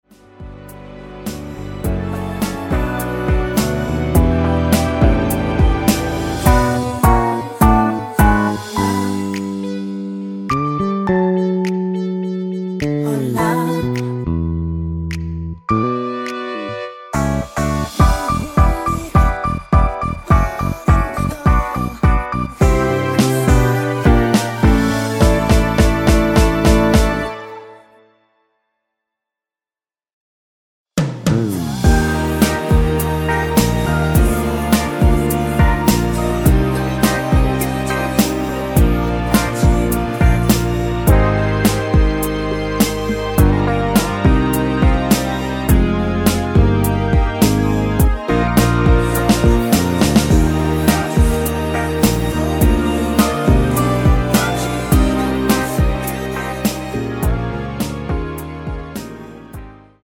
원키 코러스 포함된 MR 입니다.(미리듣기 참조)
D
앞부분30초, 뒷부분30초씩 편집해서 올려 드리고 있습니다.